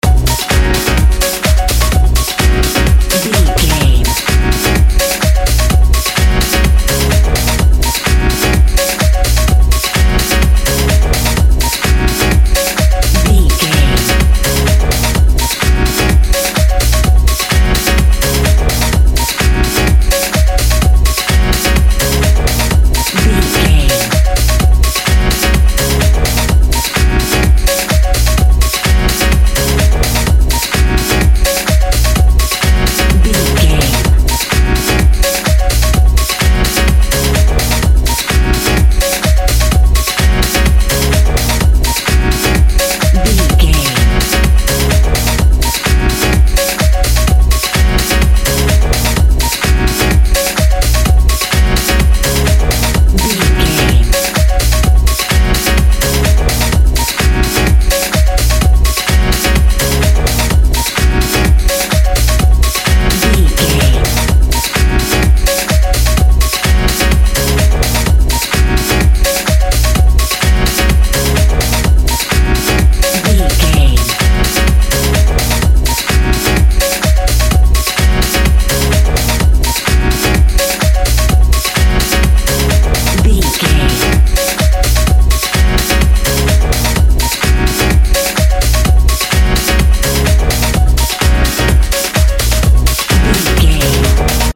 Holiday House Music.
Aeolian/Minor
E♭
funky
groovy
uplifting
driving
energetic
piano
drum machine
synthesiser
electro house
synth lead
synth bass